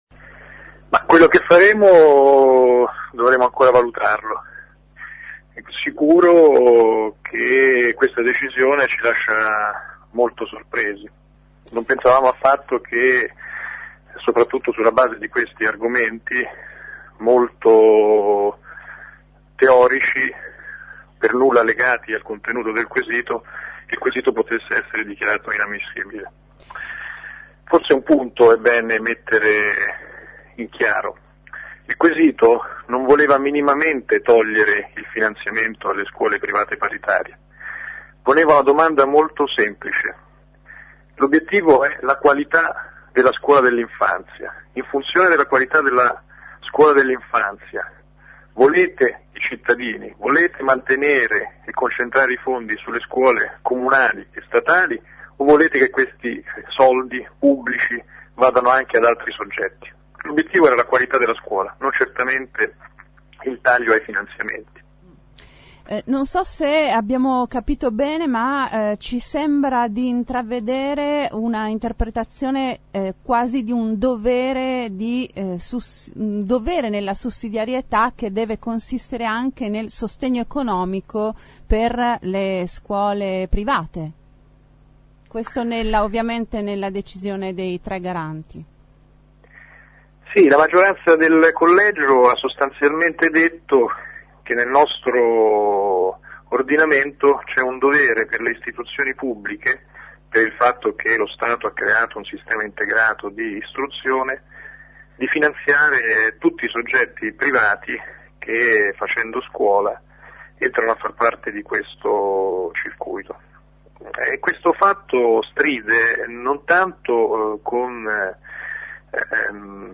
ai nostri microfoni: